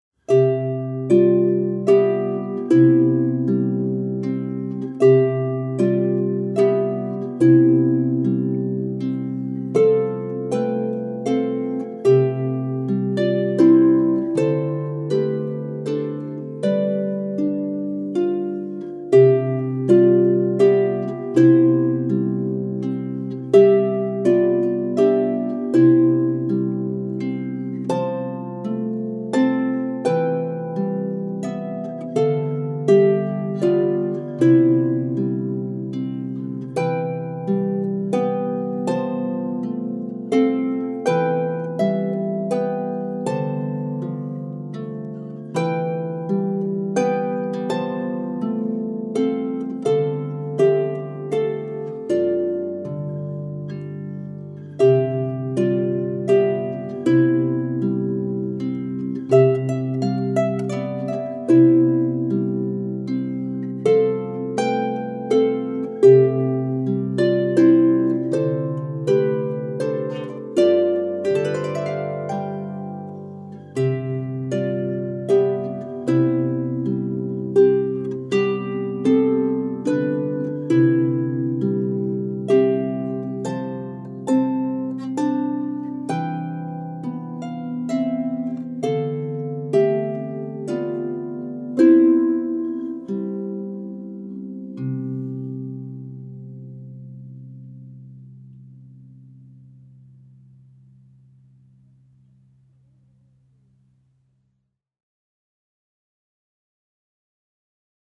solo lever or pedal harp